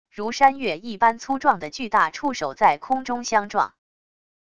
如山岳一般粗壮的巨大触手在空中相撞wav音频